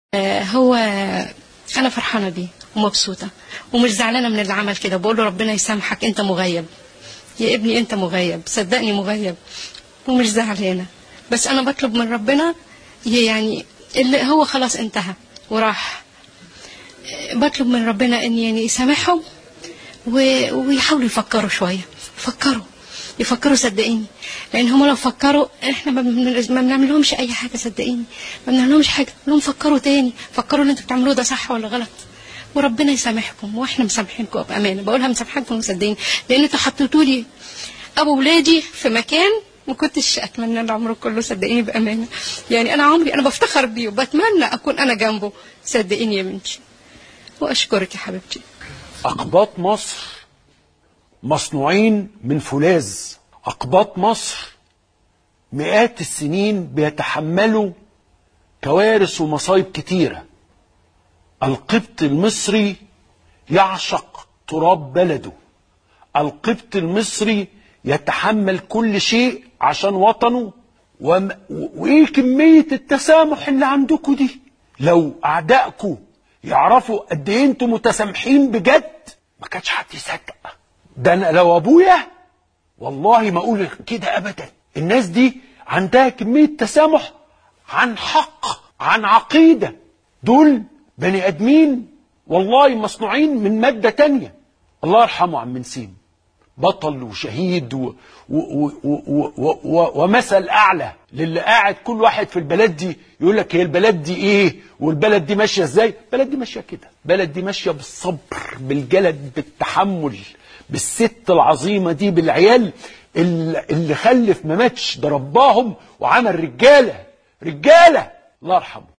يعلّق مذيع التلفزيون المصري “عمرو أديب” على كلامها: